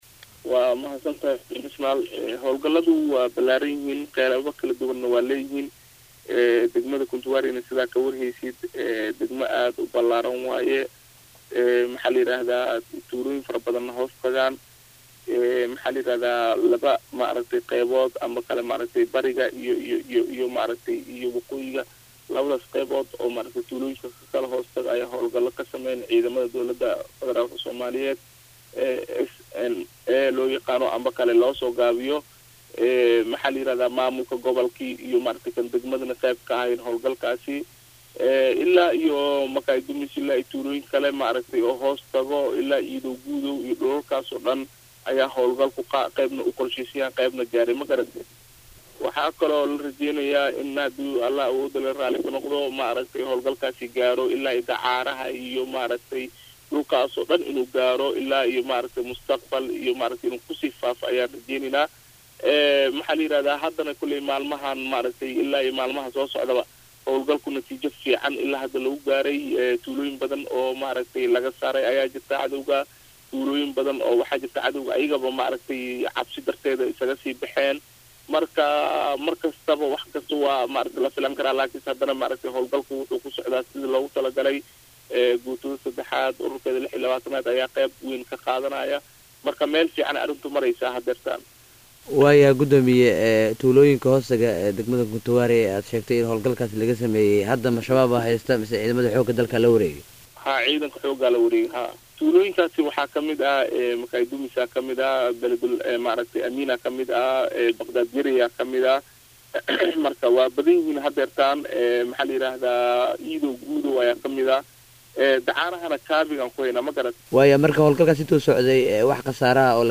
C/fitaax Cabdulle Yuusuf oo ah Guddoomiye ku xigeenka Maamulak iyo Maamliyadda Maamulka Gobolka Shabeelaha Hoose oo la hadlay Raio Muqdisho